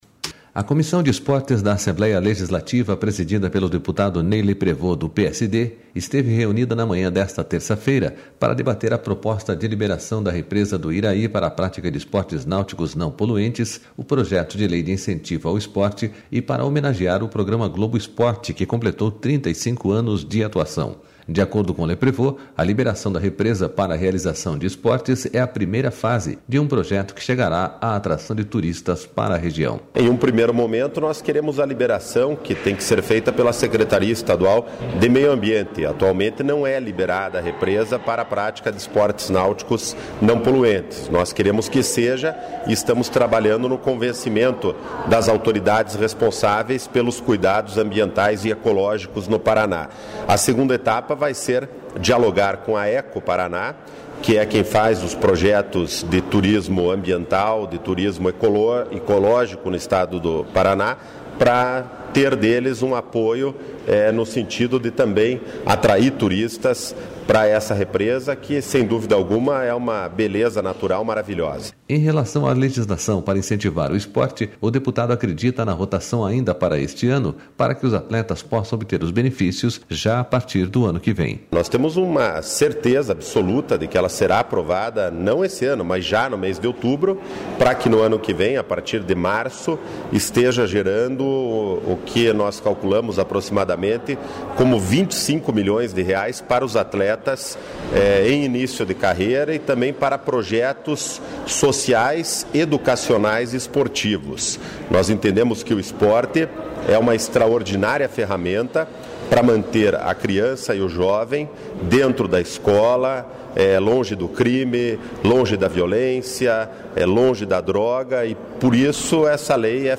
SONORA LEPREVOST
A reunião aconteceu na Sala das Comissões do Legislativo.//